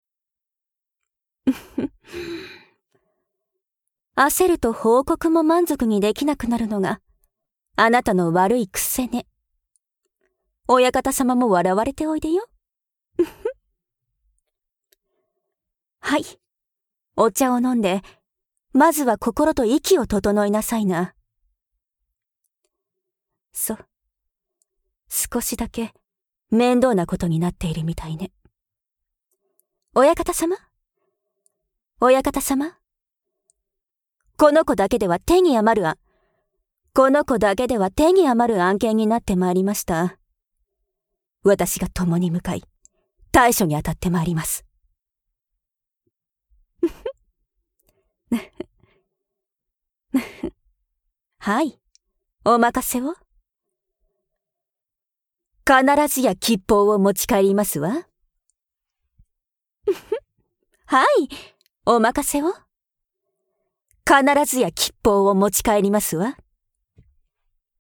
Aノイズ除去と音量アップ
ノイズを頑張って消して音量をアップさせます。リップノイズも頑張って消します。
また、セリフに被らない箇所のリップノイズや物音などはそのままになる事が多いです。